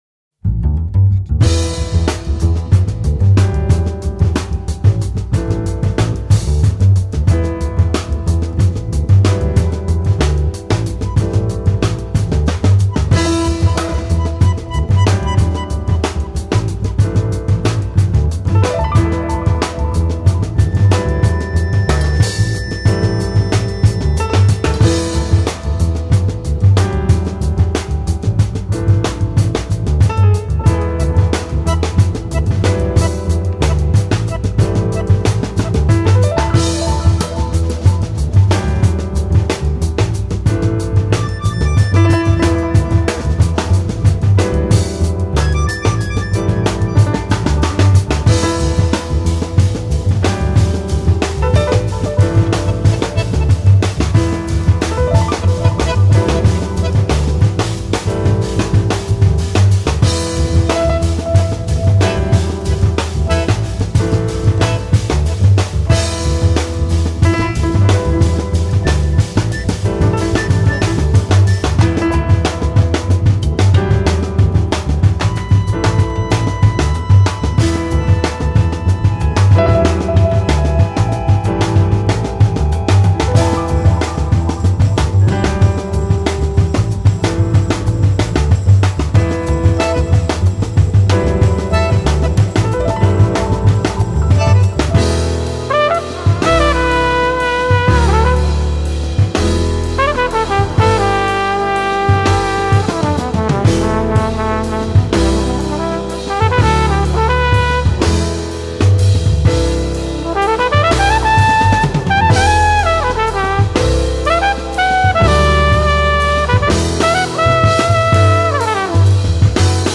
Contemporary jazz.
across the seas of jazz and tango
flugelhorn
fat bass tone can sing joyfully on top of the band
piano
bandoneon
drums